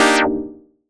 Synth Stab 17 (C).wav